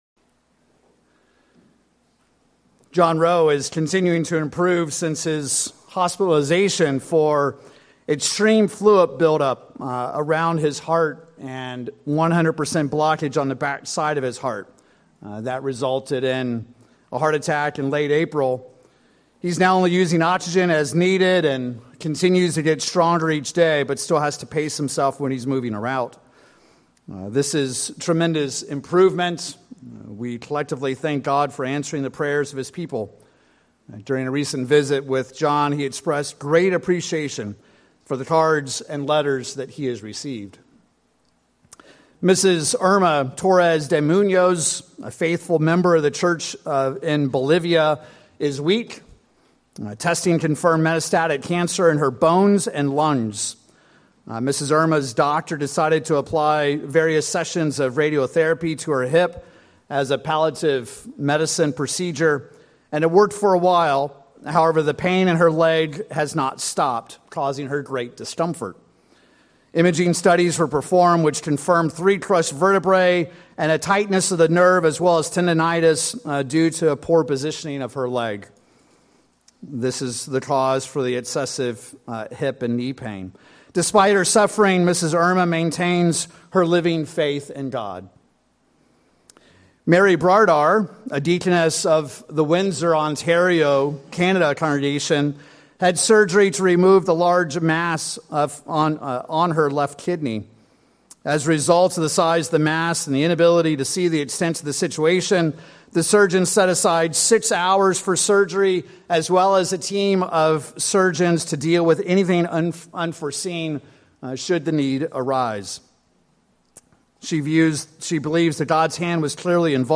Sermons
Given in Oklahoma City, OK Tulsa, OK